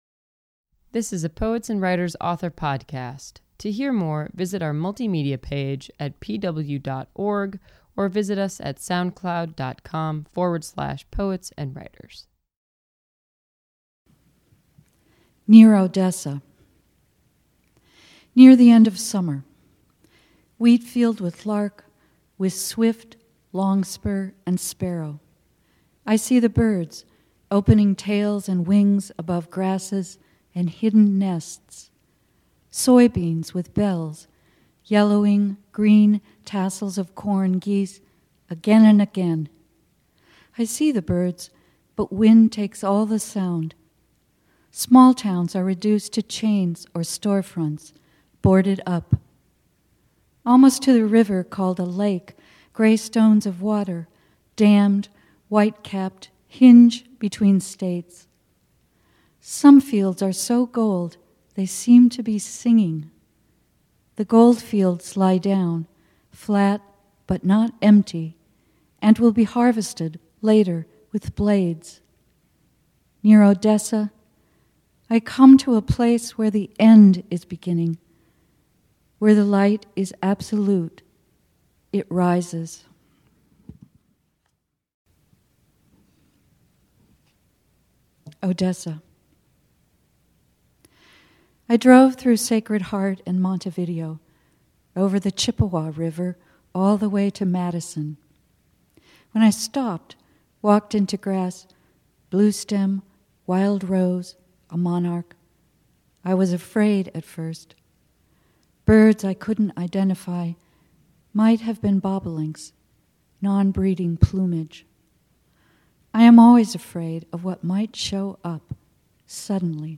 Poet
audio | poetry